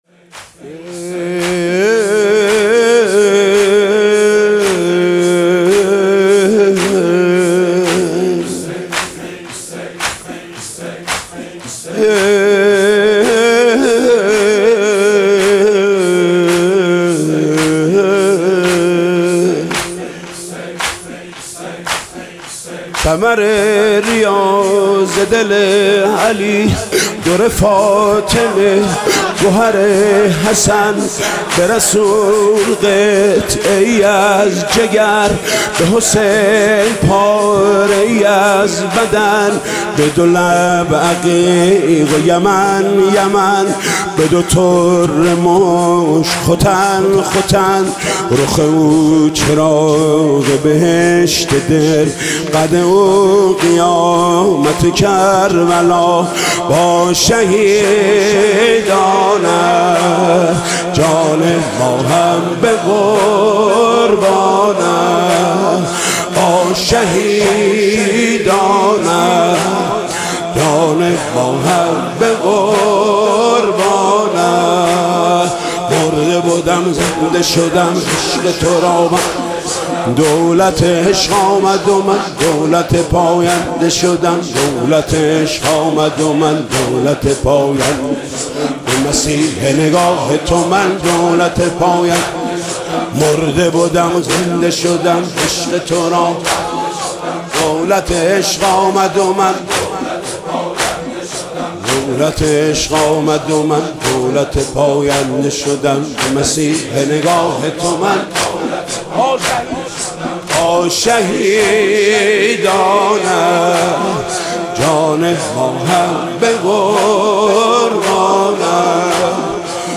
شب ششم محرم95/ هیئت رایت العباس (ع) / چیذر